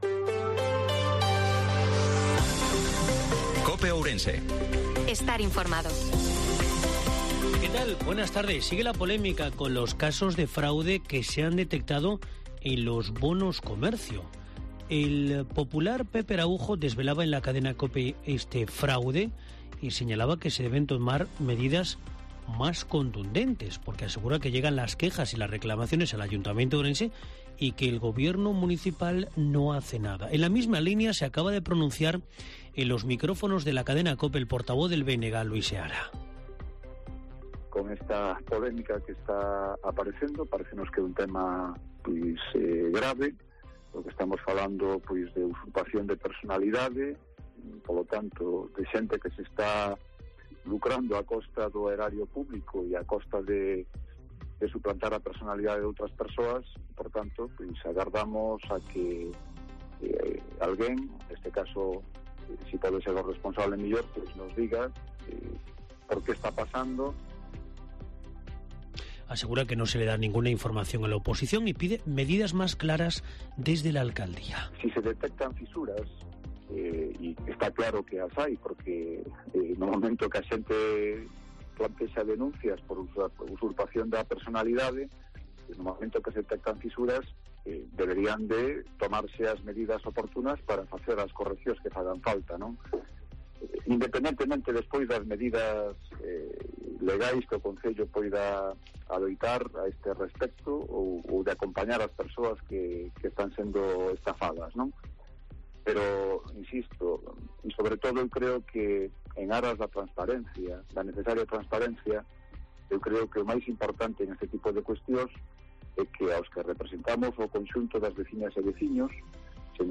INFORMATIVO MEDIODIA COPE OURENSE-25/04/2023